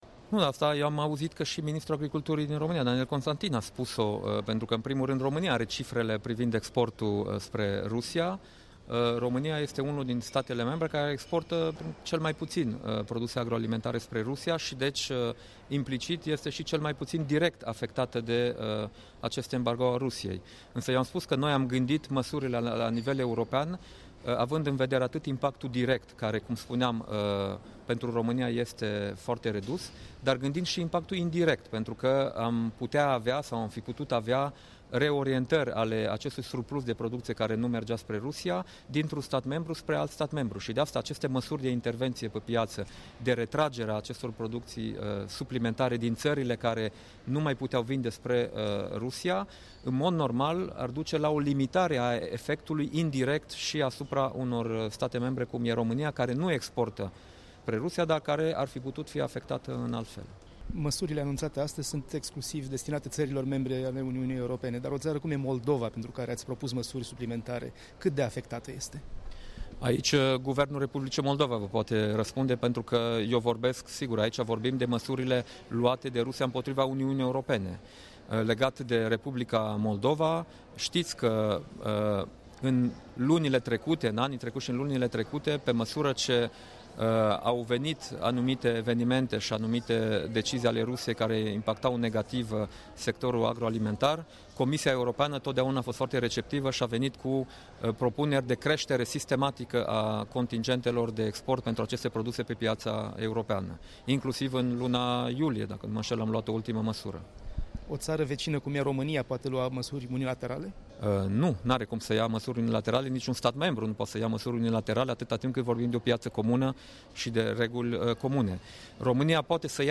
Interviu cu Dacian Cioloş